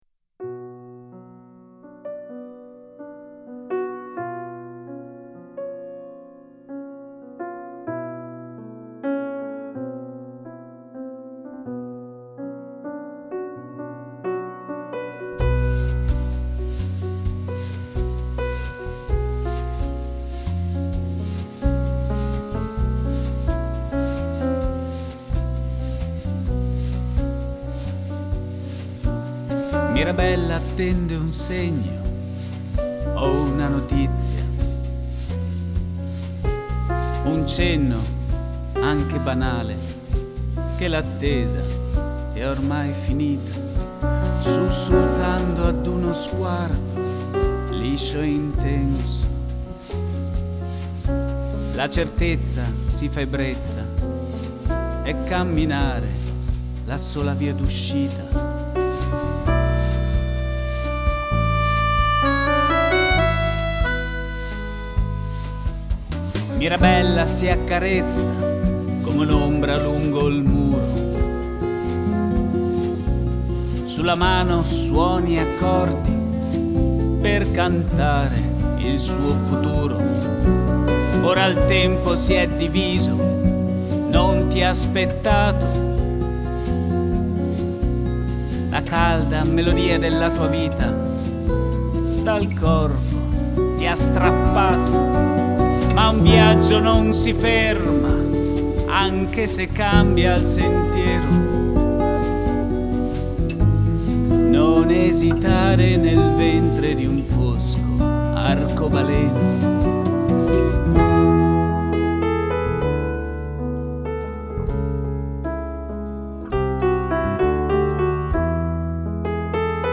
chitarra e voce
basso
tastiere
batteria